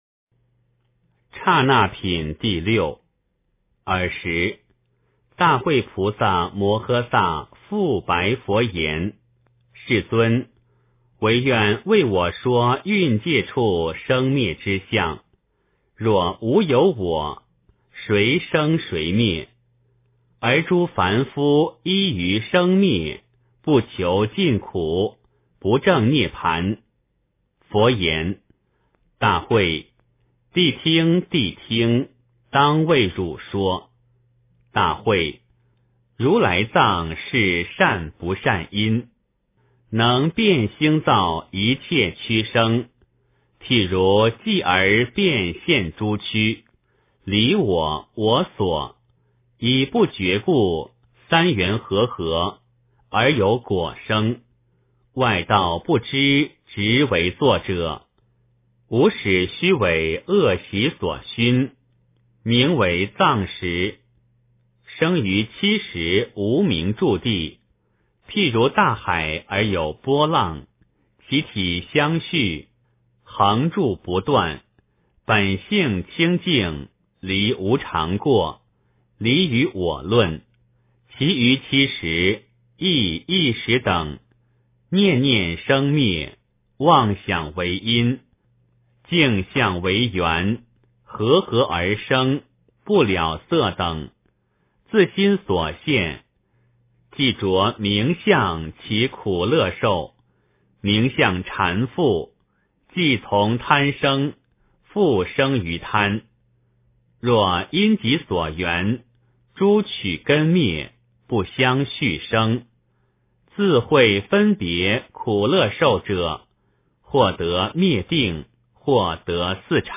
楞伽经（剎那品第六） - 诵经 - 云佛论坛